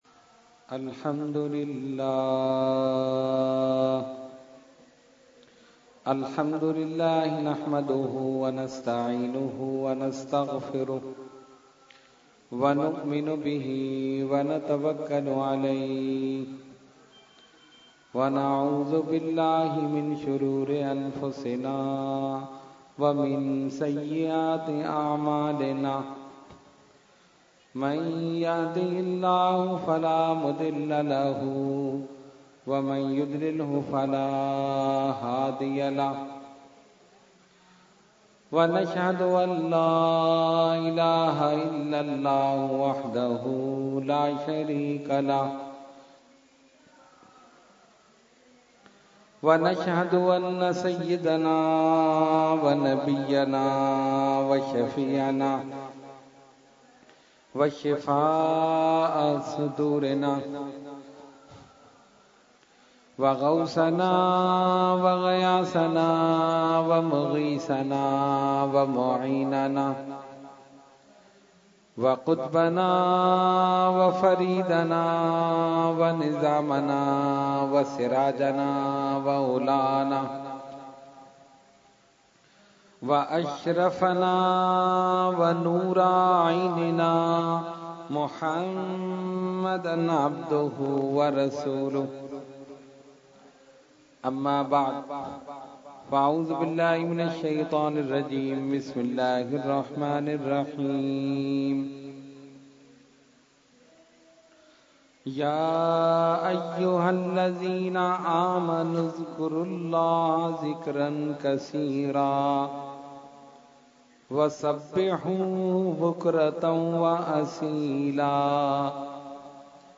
Category : Speech | Language : UrduEvent : Muharram 2017